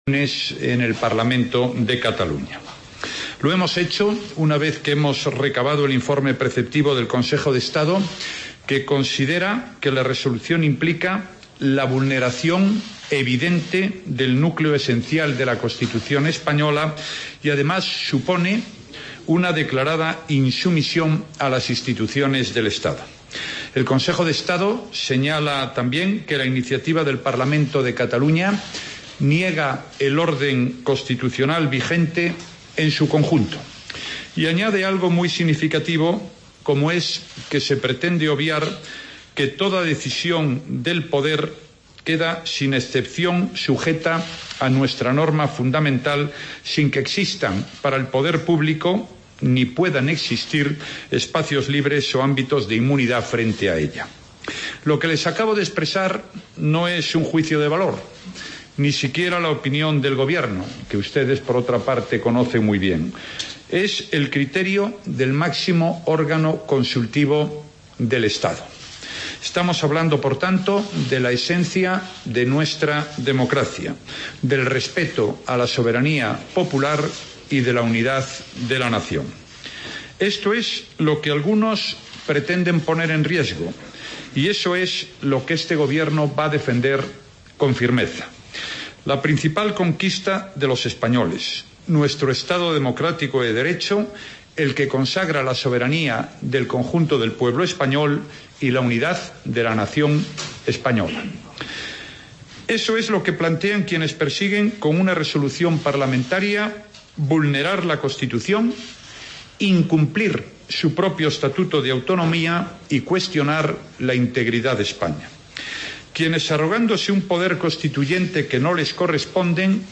AUDIO: Presentación, avance informativo y Raúl Valcarce (alcalde de Carracedelo)